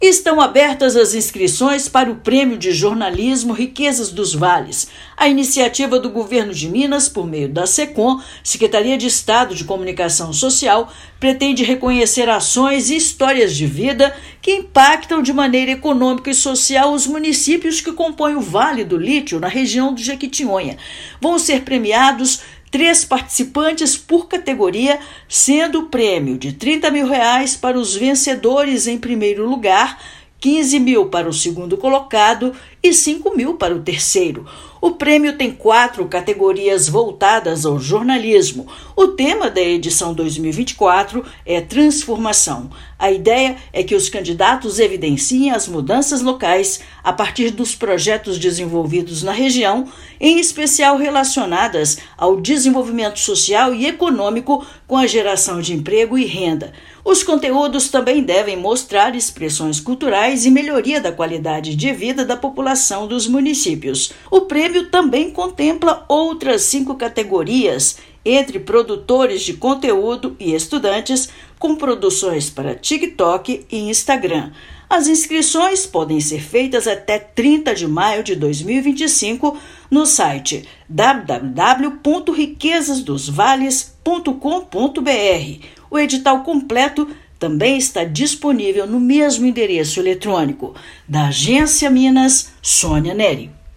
[RÁDIO] Vale do Lítio: Governo de Minas lança prêmio de jornalismo com premiação de até R$ 50 mil por categoria